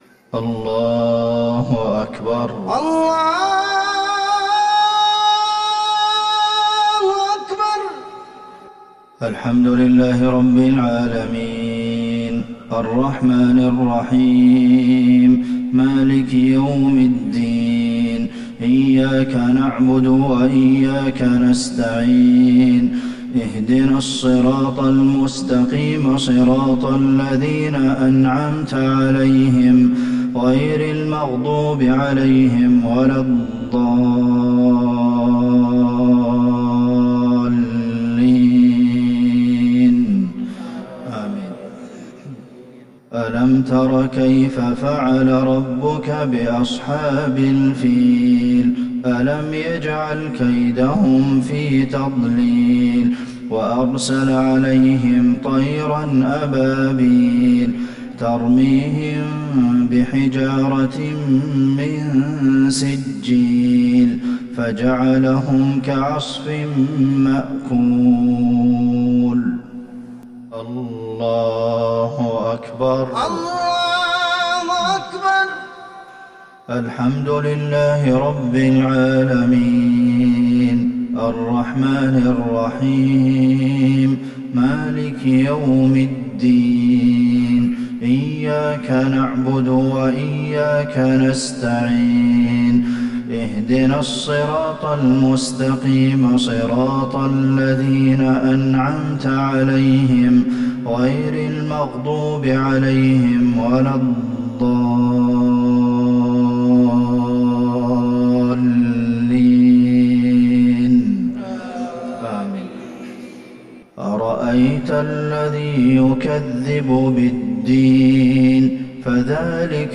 صلاة المغرب للشيخ عبدالمحسن القاسم 15 رجب 1441 هـ
تِلَاوَات الْحَرَمَيْن .